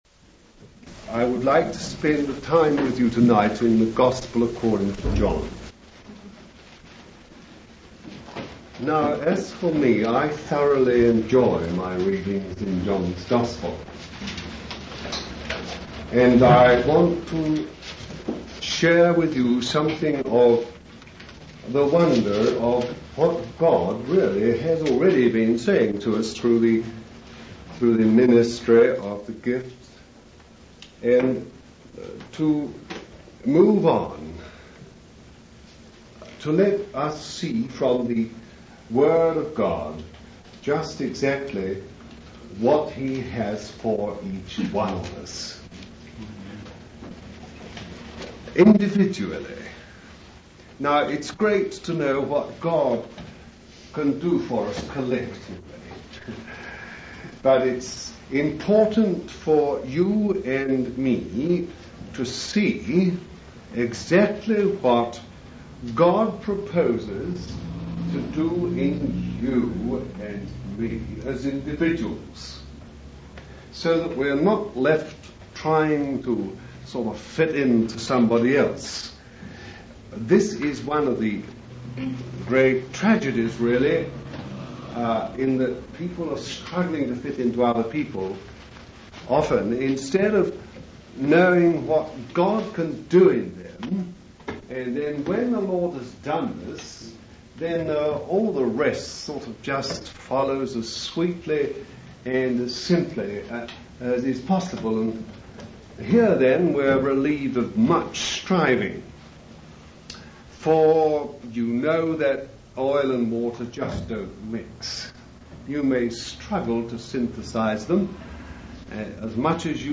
In this sermon, the preacher focuses on the Gospel of John and takes his time to explore its truths. He starts by referencing the story of Jesus speaking to the woman at the well in John 4.